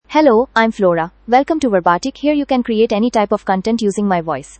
FemaleEnglish (India)
Flora is a female AI voice for English (India).
Voice sample
Flora delivers clear pronunciation with authentic India English intonation, making your content sound professionally produced.